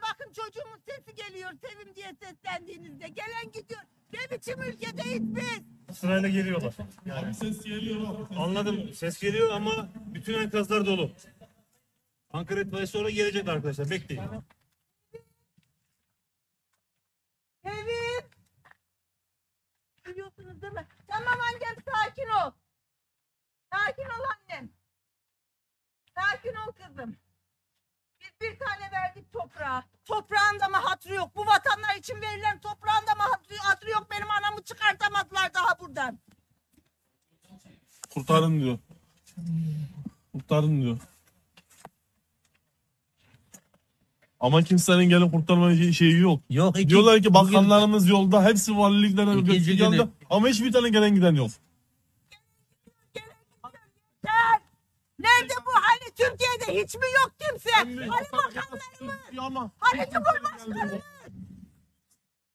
Deprem mağdurlarının yardım çığlıklarını da, bu çığlıklara “şerefsiz, haysiyetsiz” diyenleri de unutma!
Deprem mağdurlarının sosyal medyada paylaştığı videoları tarayıp ses dosyalarına dönüştürerek internet sitesine yükledik.